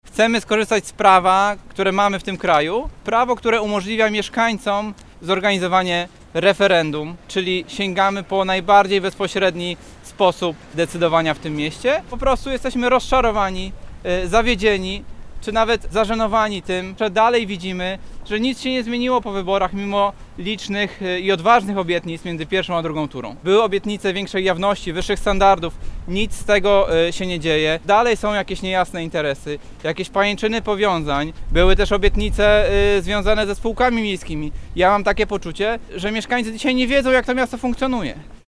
Jakub Nowotarski, radny miejski i prezes Akcji Miasto przekonuje, że system demokratyczny zapewnia dostęp do bezpośredniej formy decydowania. Referendum jest akcją oddolna, ma być zorganizowane bez udziału partii politycznych.